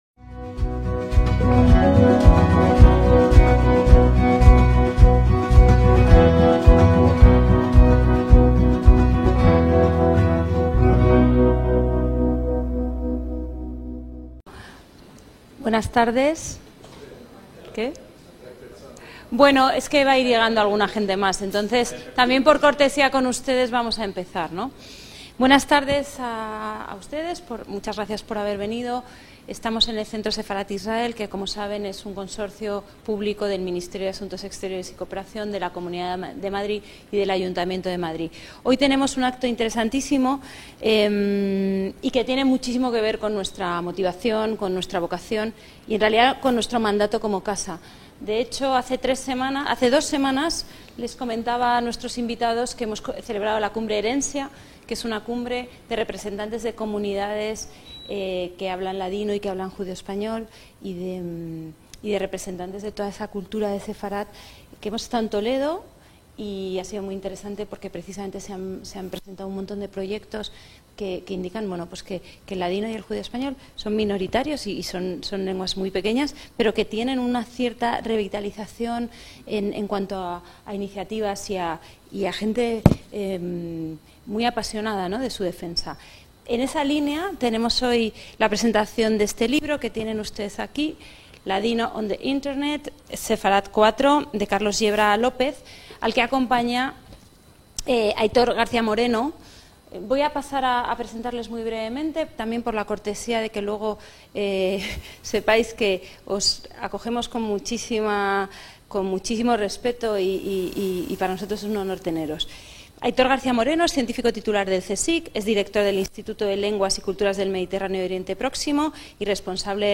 Presentación: Un nuevo proyecto sobre el judeo español (Centro Sefarad Israel, Madrid, 24/6/2025)